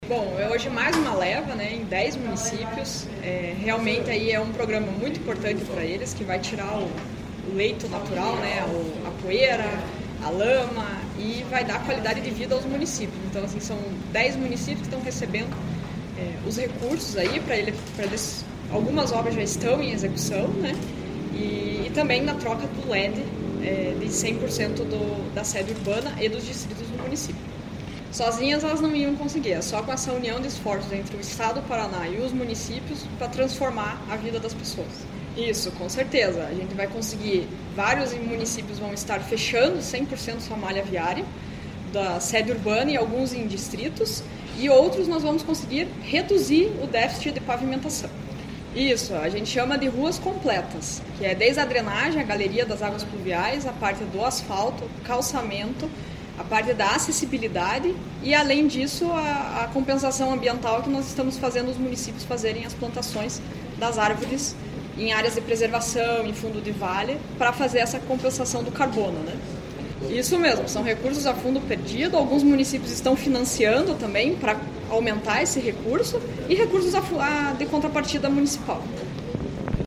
Sonora da secretária Estadual das Cidades, Camila Scucato, sobre a liberação de recursos para 10 municípios através do programa Asfalto Novo, Vida Nova